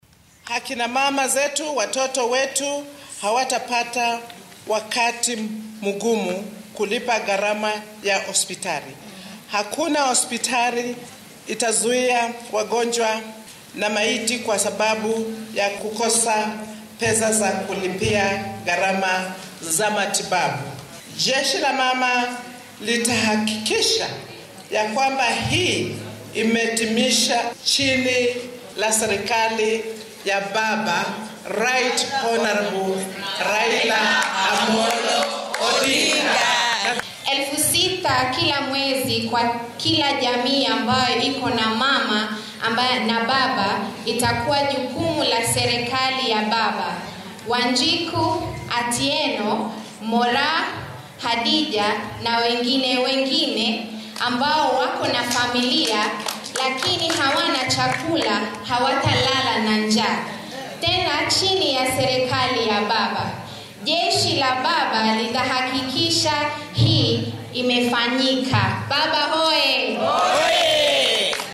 Barasaabka ismaamulka Kitui Charity Ngilu uu ka mid ah haweenka qabtay shirka jaraaid ee ay ku shaaciyeen inay u ololeyn doonaan Mr. Odinga ayaa warbaahinta la hadashay